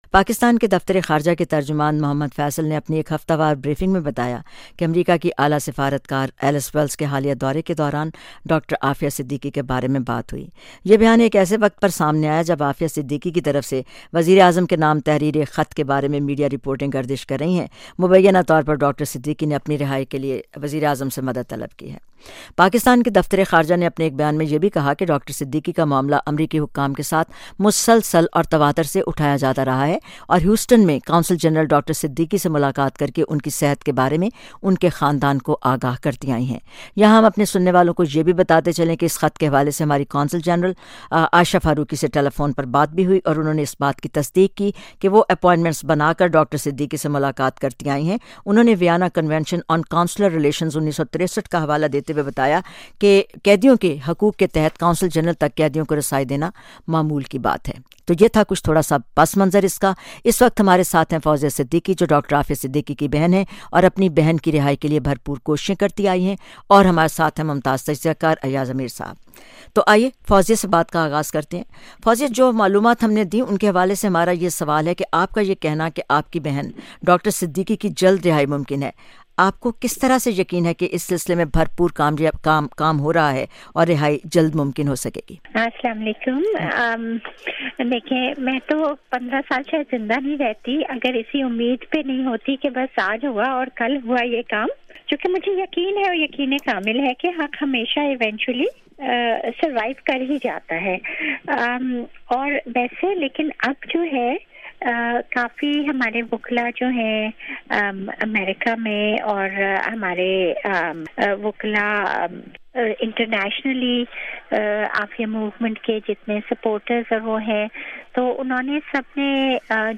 Jahan Rang Discussion, 'Dr. Afia Siddiqui in the news again'